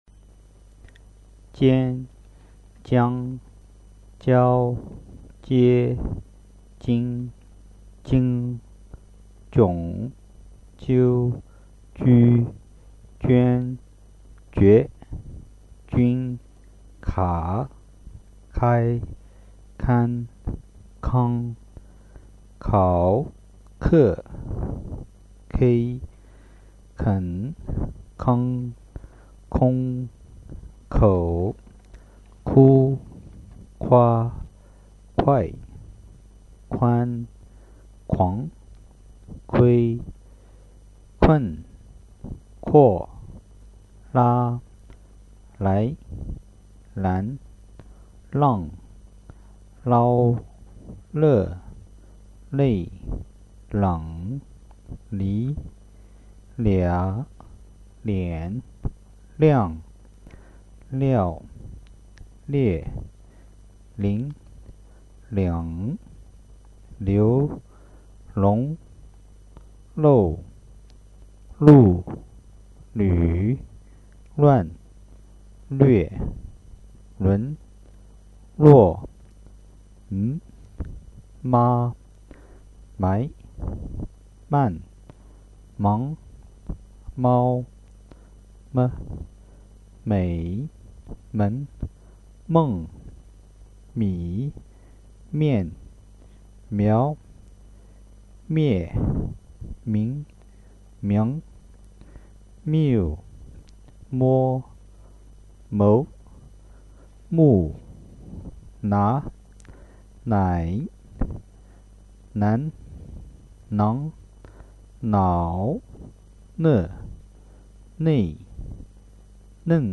The sounds and description list of the Chinese basic syllables